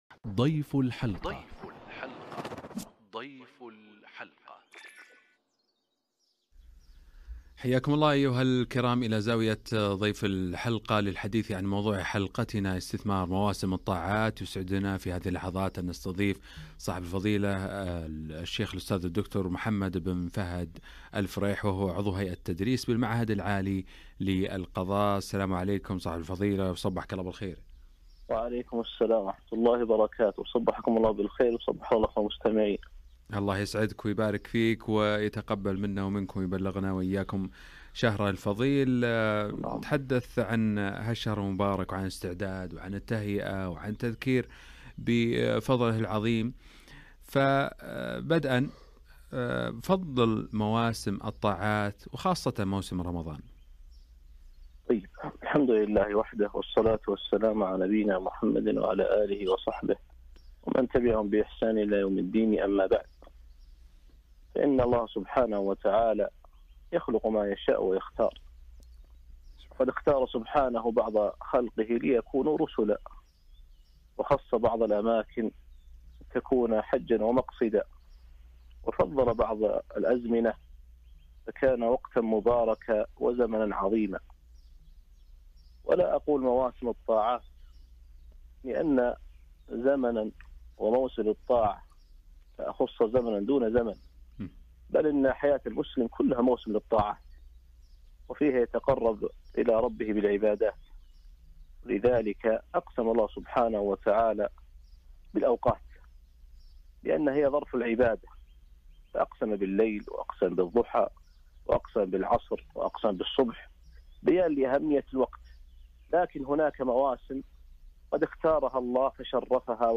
استثمار مواسم الطاعات - لقاء إذاعي